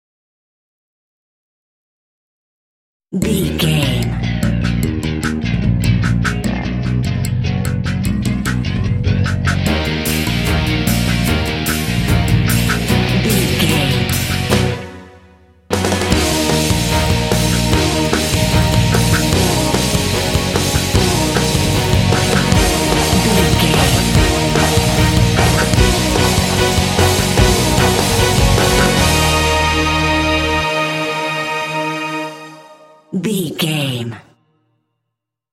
Epic / Action
Fast paced
Ionian/Major
powerful
heavy
synthesiser
drums
strings
heavy metal
hard rock